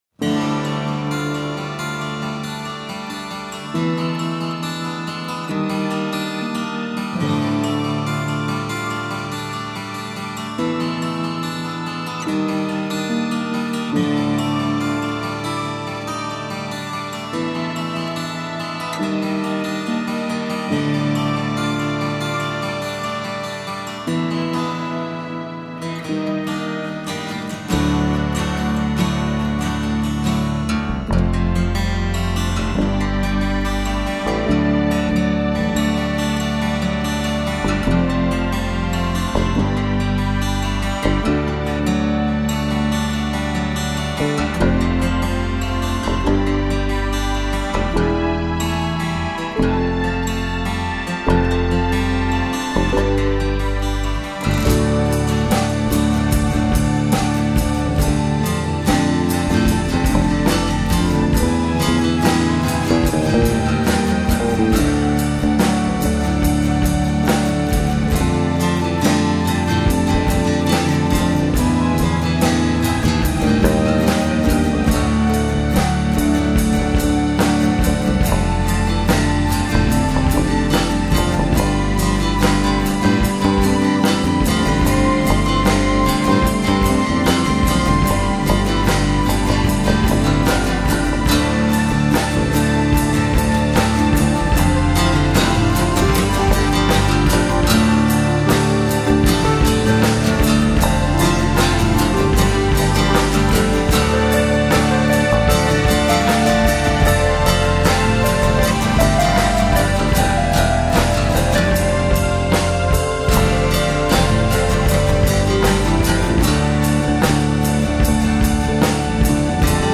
at various locations - 2007
Guitar, Keyboards
Drums
Piano, Keyboards
Bass Guitar